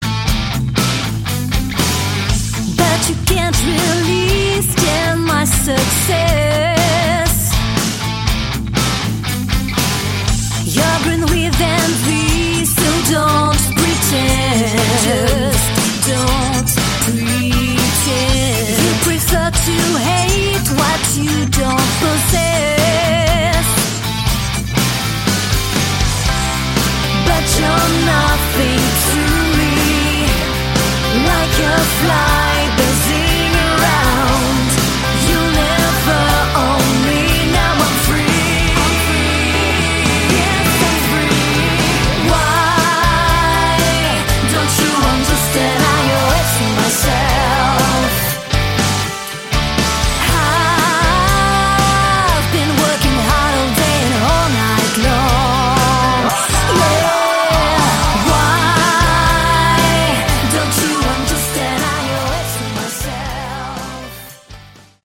Category: Melodic Rock
lead and backing vocals